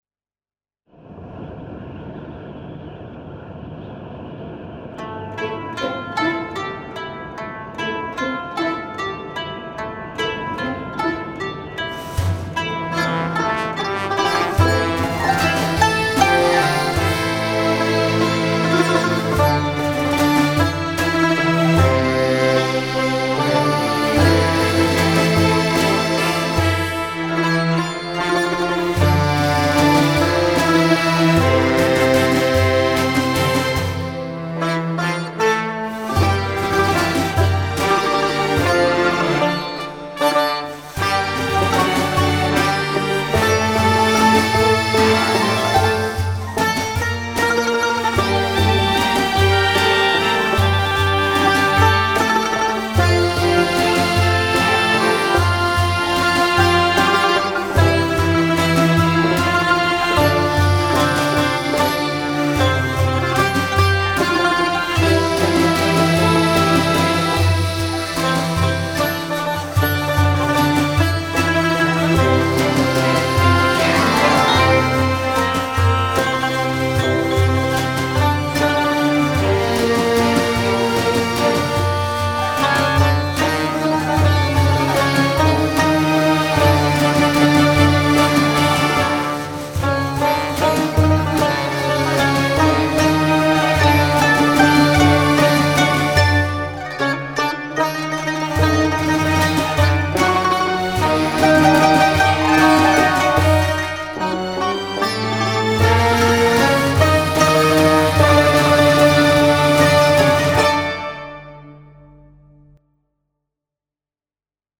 Bi Kalaam